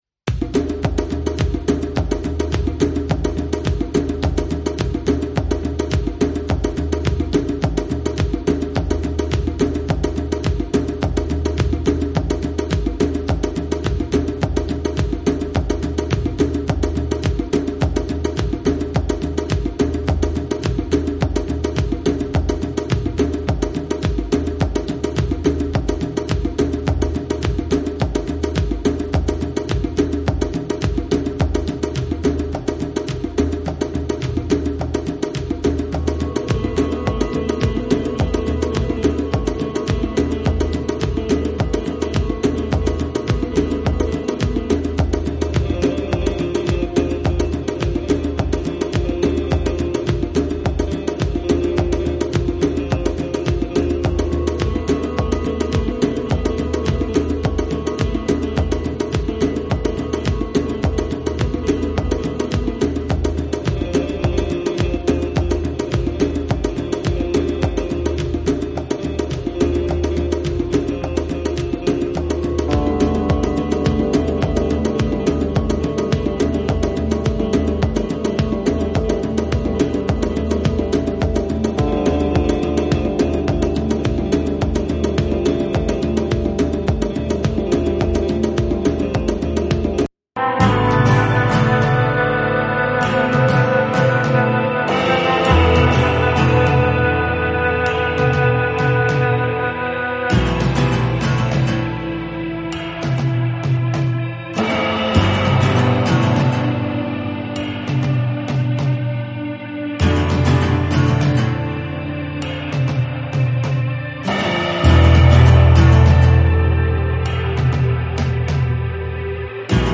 JAPANESE / TECHNO & HOUSE / NEW RELEASE(新譜)